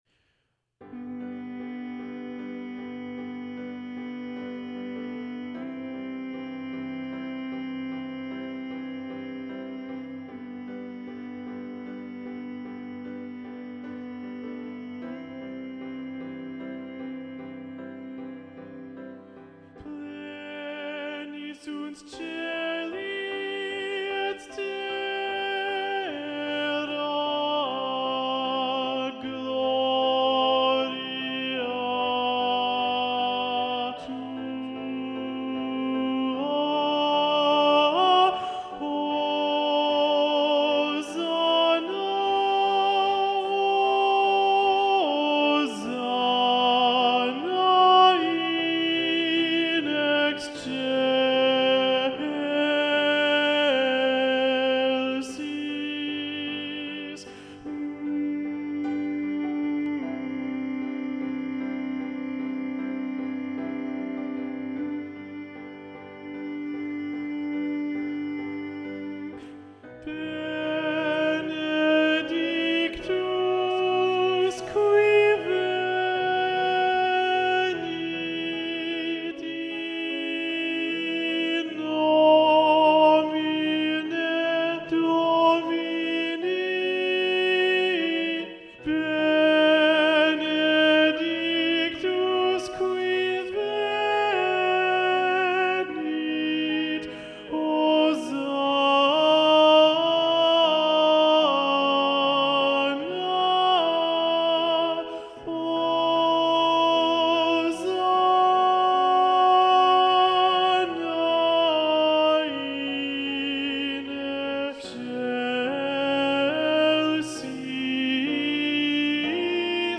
Alto 1
The-Ground-Alto-1-Predominant-Ola-Gjeilo.mp3